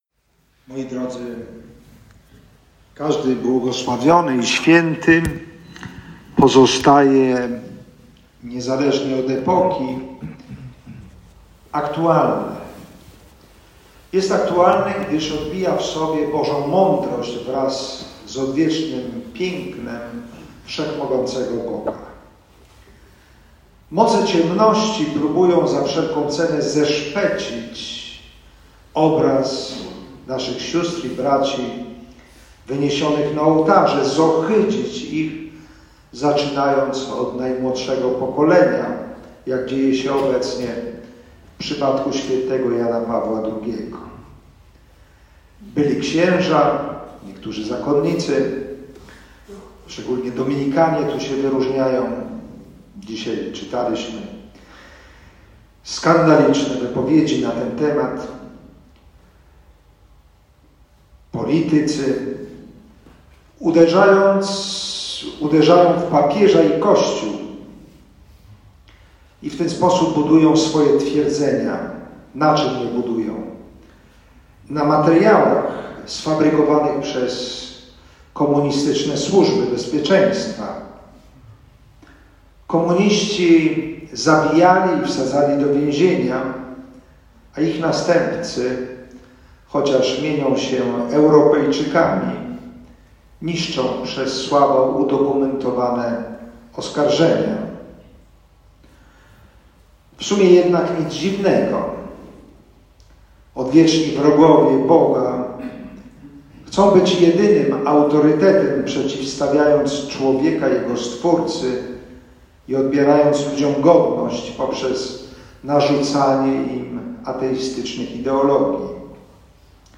Mszy świętej przewodniczył biskup sandomierski Krzysztof Nitkiewicz, który w homilii odwołał się do dziedzictwa bł. Wincentego:
Homilia-Bp-Wlostow-.mp3